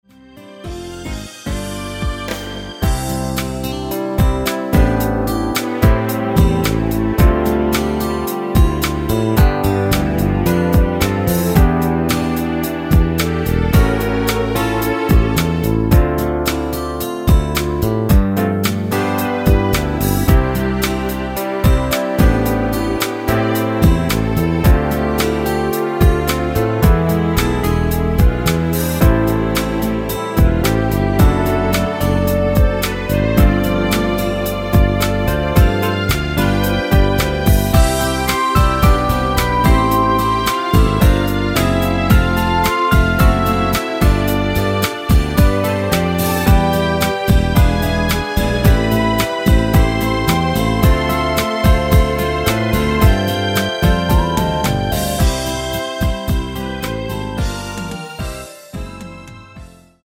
원키 멜로디 포함된 MR 입니다.(미리듣기 참조)
앞부분30초, 뒷부분30초씩 편집해서 올려 드리고 있습니다.
중간에 음이 끈어지고 다시 나오는 이유는
(멜로디 MR)은 가이드 멜로디가 포함된 MR 입니다.